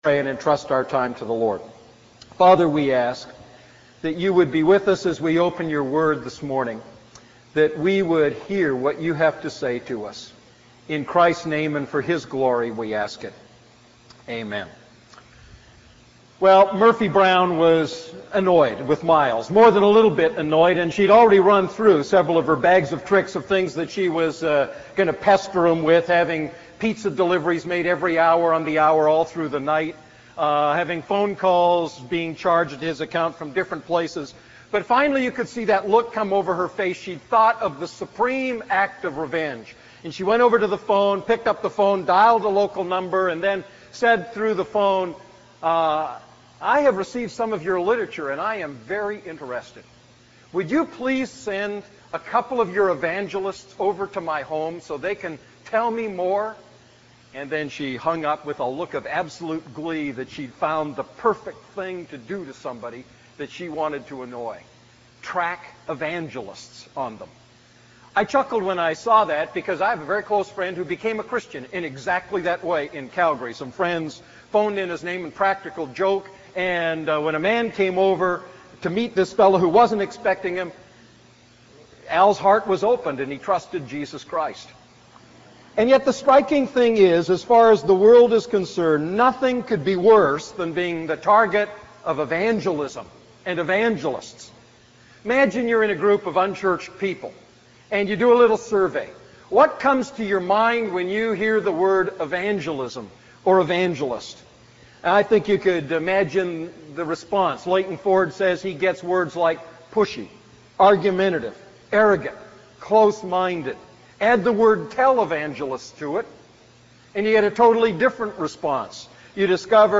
A message from the series "1 Thessalonians."